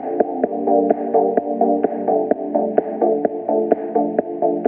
elevator-music.mp3